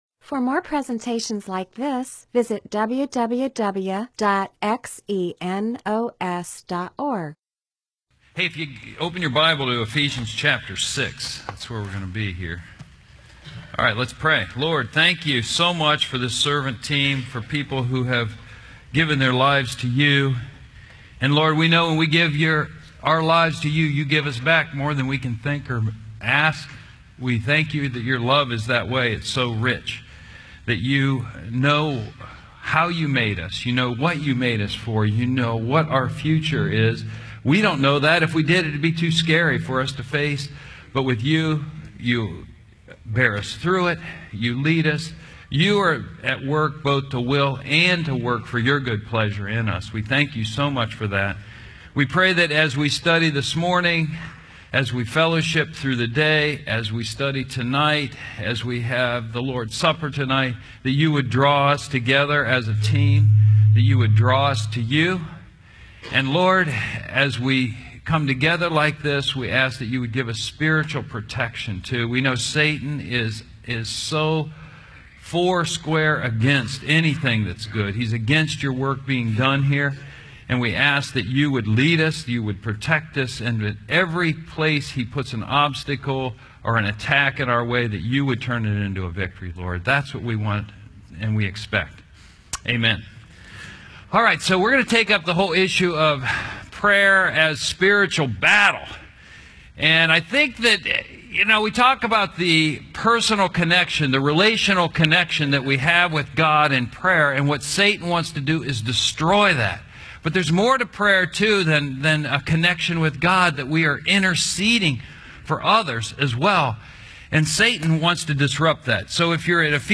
MP4/M4A audio recording of a Bible teaching/sermon/presentation about Ephesians 6:10-18.